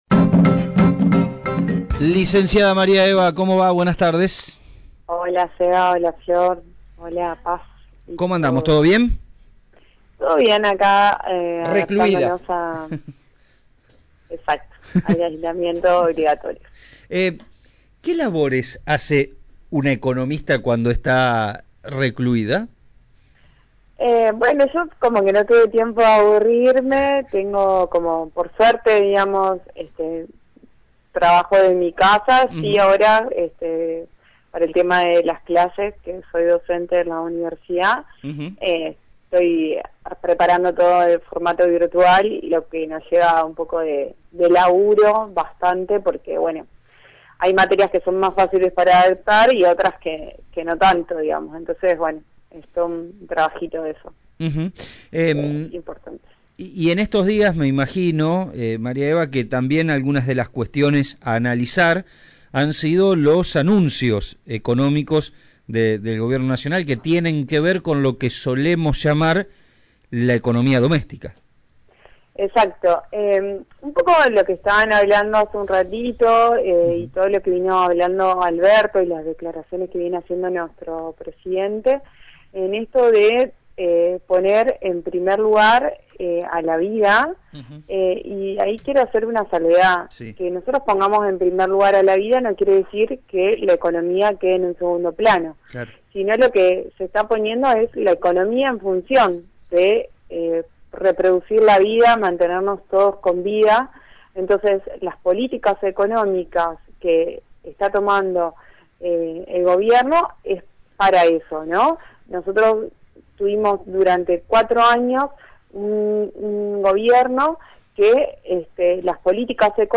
En la tarde de la Fm Chalet estuvimos en dialogo